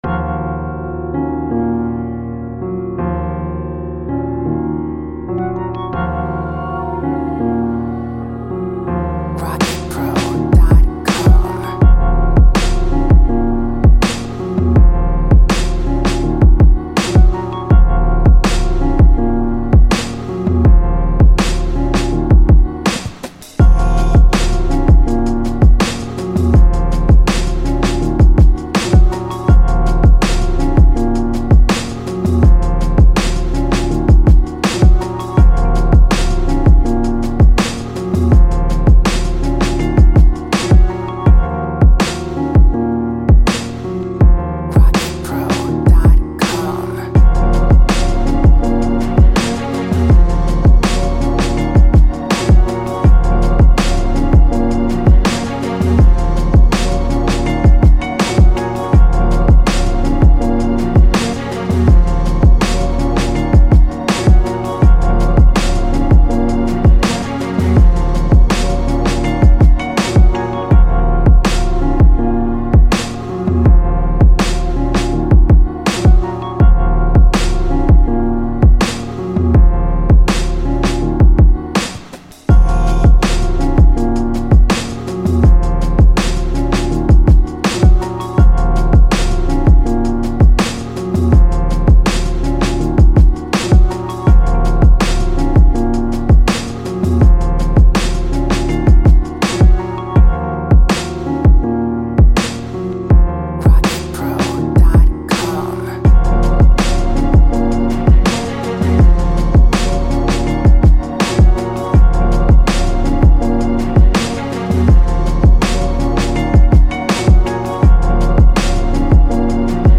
Chill
type beat with moving drums and pianos.
140 BPM.